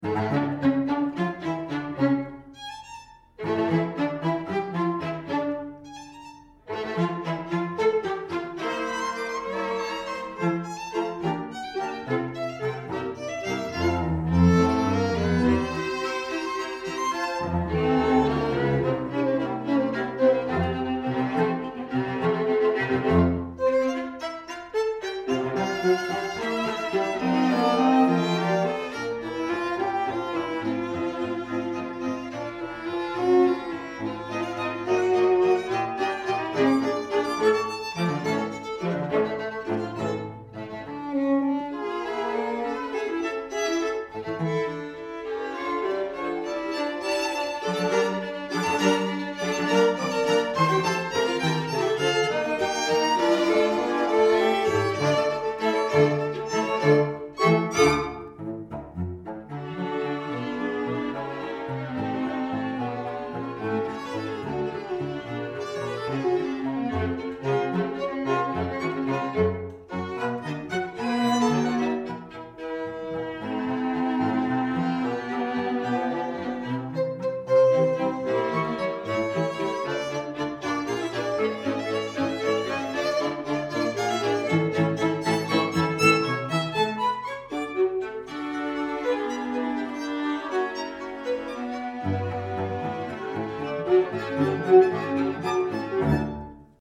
Soundbite 3rd Movt
For 2 Violins, 2 Violas and Violoncello